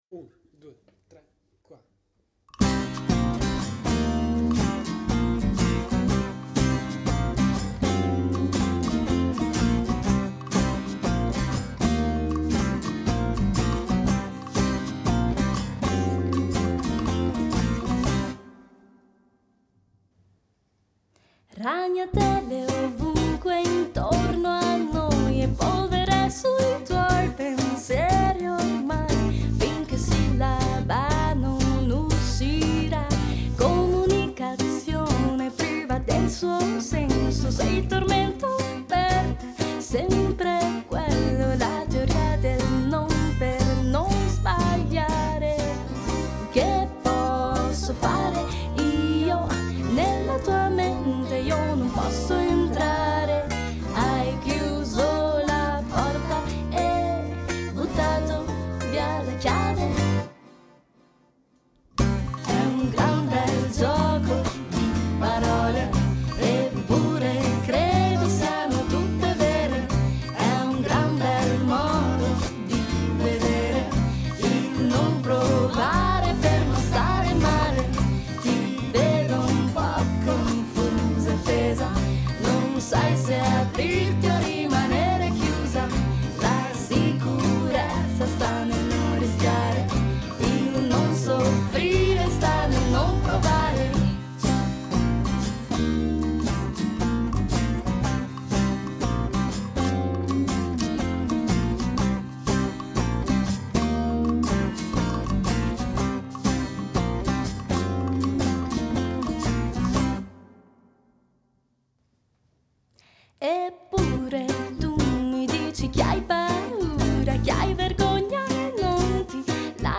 basso
cori e voce solista finale